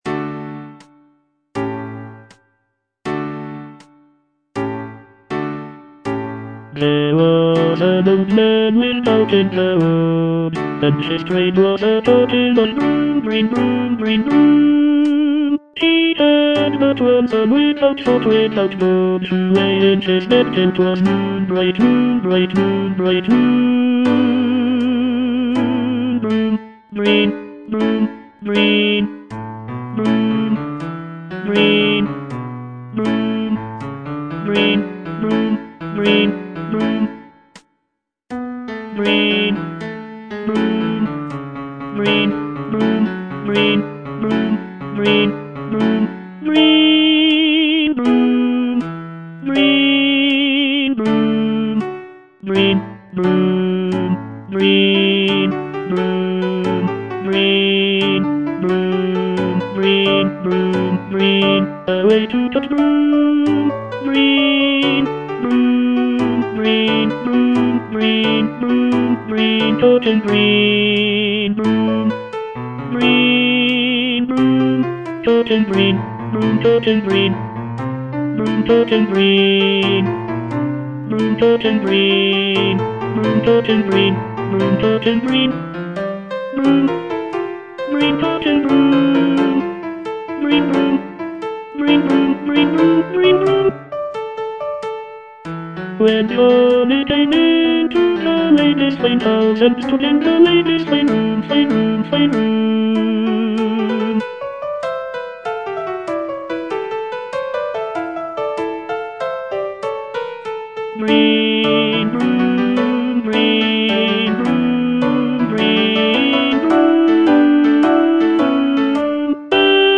Tenor II (Voice with metronome)